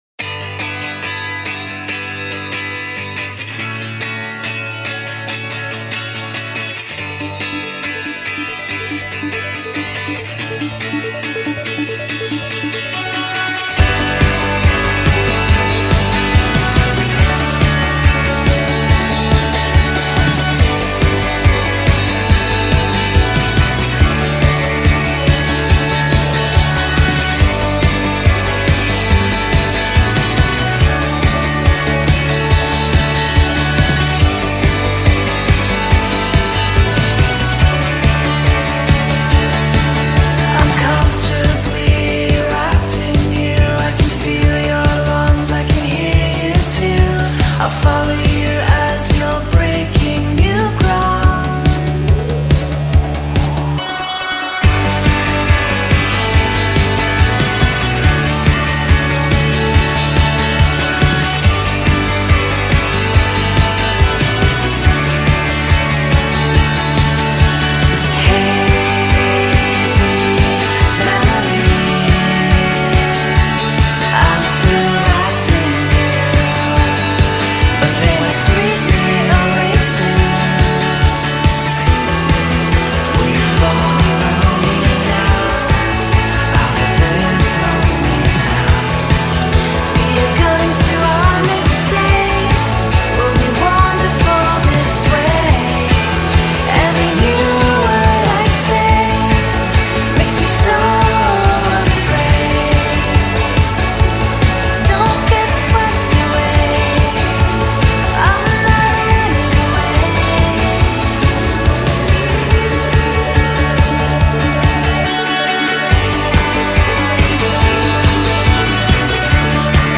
indie electro-pop